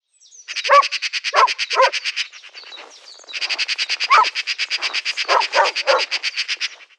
Un chien joue dans la neige. Il dérange des pies qui s’envolent en jacassant. Pinsons, mésanges et rouges-gorges se chamaillent autour d’une mangeoire, une hermine gazouille. Une famille de sangliers grognent dans les sous-bois…
Les sons vont au-delà des simples cris des animaux. Ils restituent l’univers de ce moment de la journée, à la campagne.